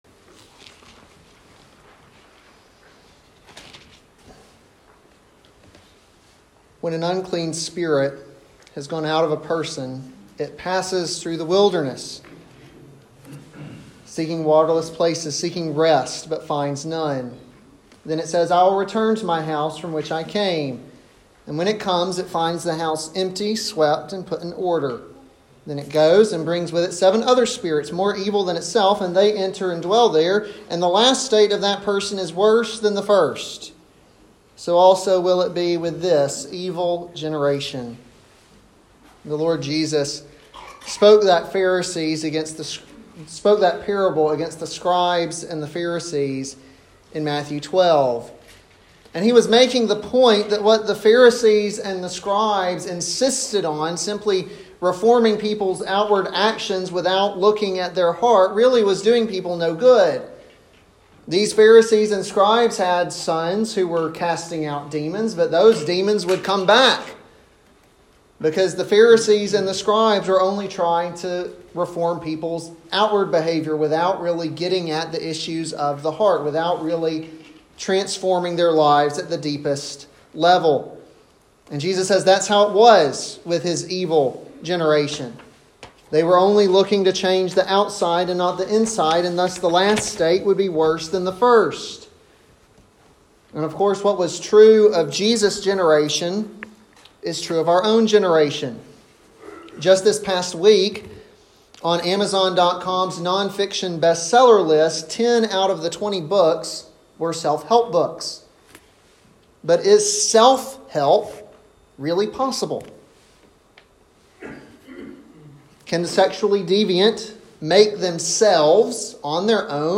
an expository sermon on Galatians 5:16-25